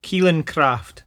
[KEEL-in crahft]